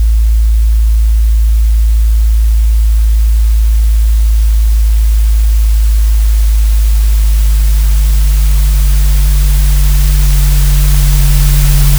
I modeled a 12-second stress curve for a massive, weathered timber beam—the kind I see in the skeletons of the old textile factories here in Pittsburgh.
It starts with the fundamental hum. The resonance of the building just… existing. But as the load increases, the pitch drops. The material stretches. It starts to detune. Then come the groans—inharmonic partials that shouldn’t be there. Dissonance. And finally, the micro-fractures—the random, granular spikes of fibers snapping.
If you listen closely, you can hear the entropy rise. The “hiss” isn’t just a noise floor—it’s the heat. It’s the energy the system is spending just to keep from collapsing. It’s the sound of a building flinching before it fails.